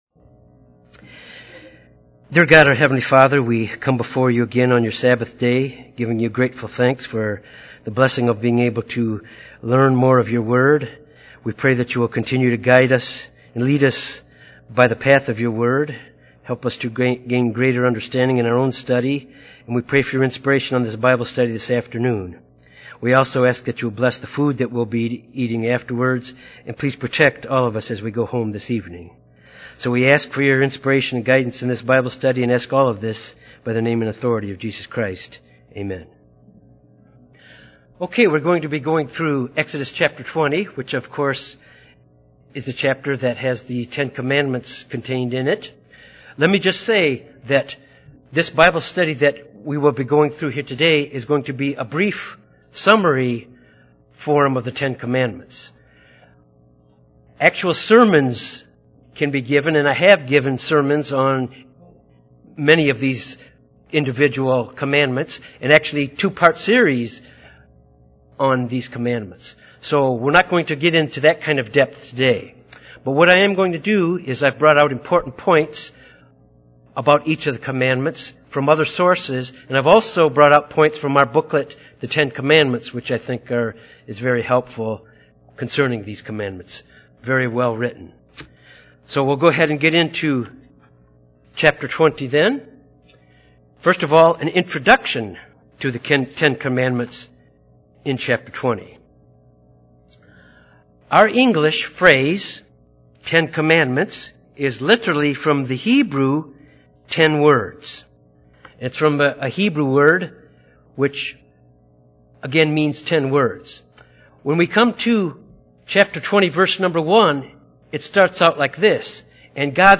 This Bible Study covers the first recorded time that God gave the 10commandments.